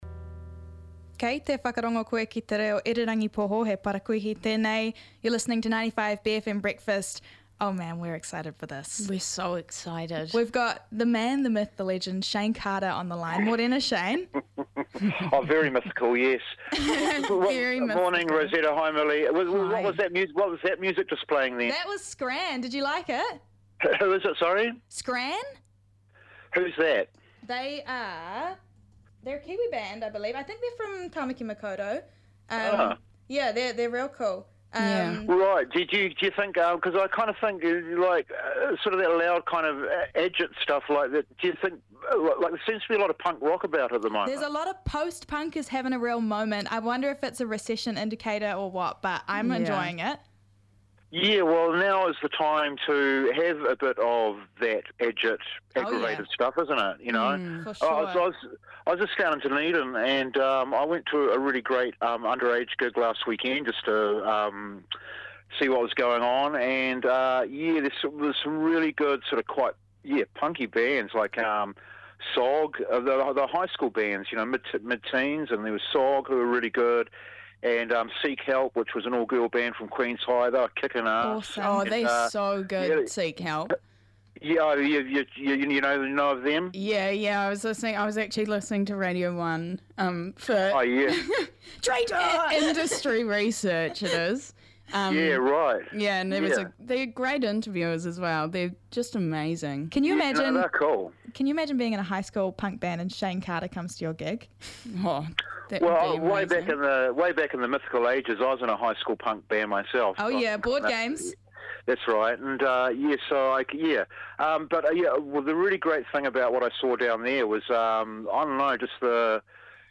Guest Interview w/ Shayne Carter: Rāmere September 12, 2025